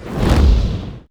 fire1.wav